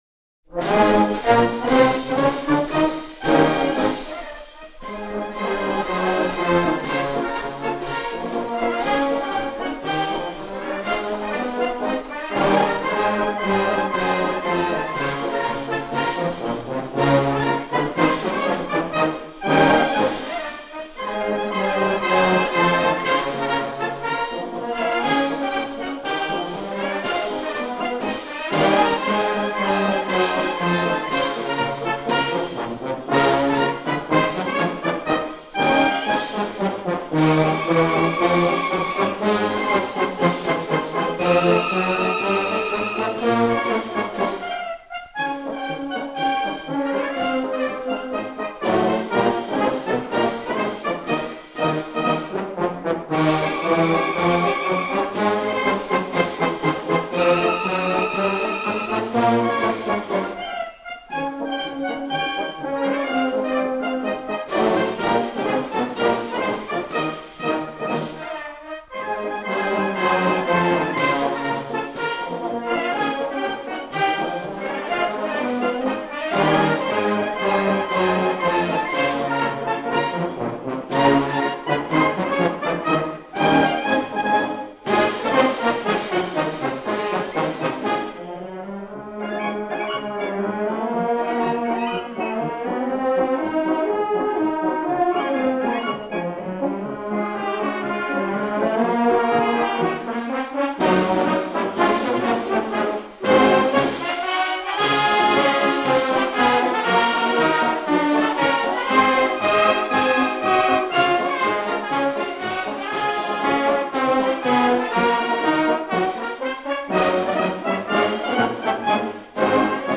Описание: Малоизвестный довоенный марш.